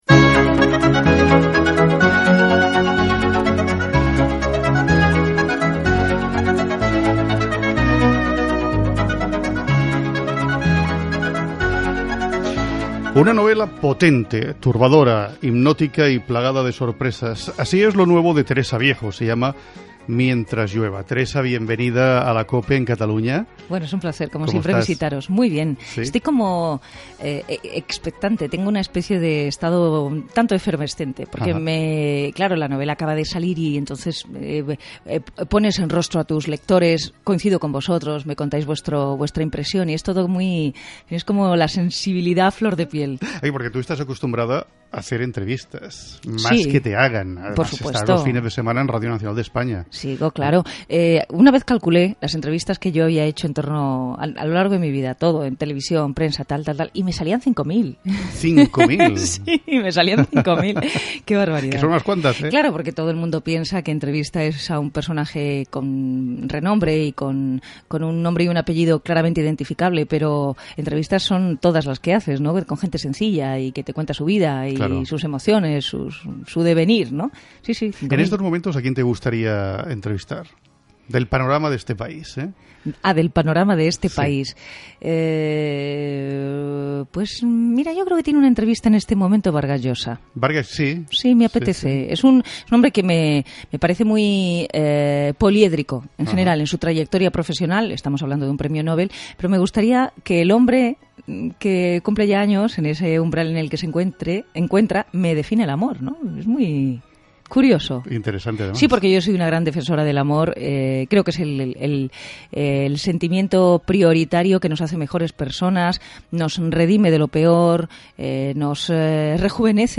Entrevista a la presentadora Teresa Viejo que ha publicat el llibre 'Mientras Llueva' . S'hi parla de la seva vessant com a entrevistadora i de la seva novel·la (el personatge, la importància dels finals...)
Entreteniment